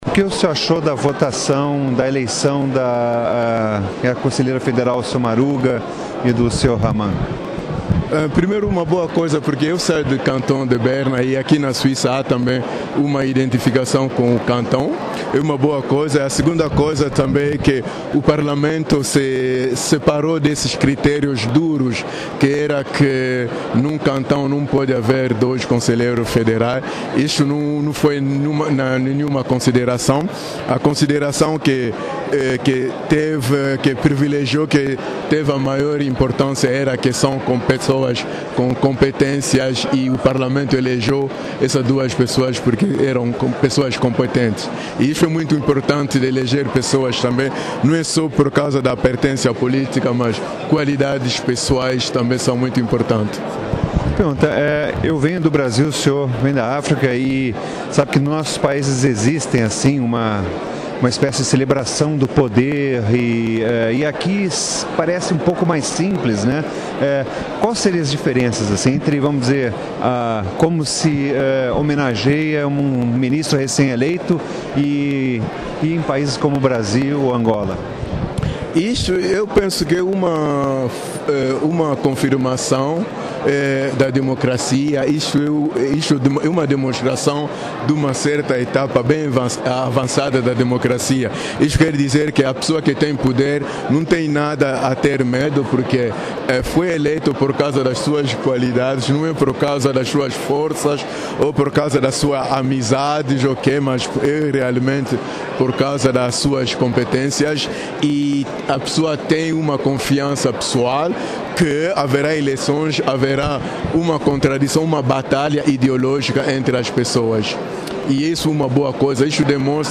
Entrevista com Ricardo Lumengo sobre eleição de novos ministros